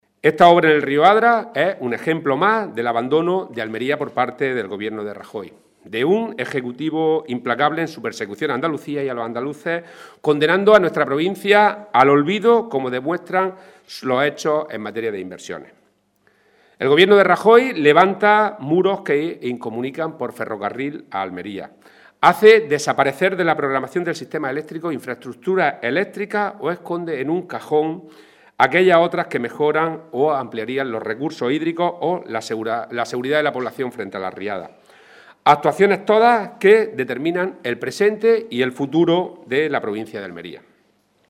Rueda de prensa sobre el río Adra que ha ofrecido el parlamentario andaluz del PSOE de Almería, Rodrigo Sánchez